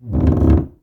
1237 Door 08B Creak 1s 0.02 MB